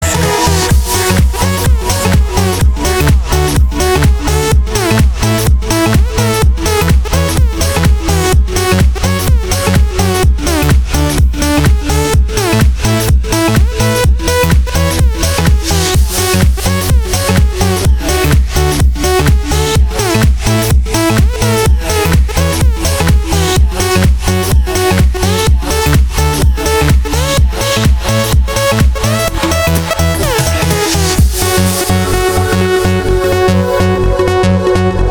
громкие
Electronic
EDM
без слов
Trance
звонкие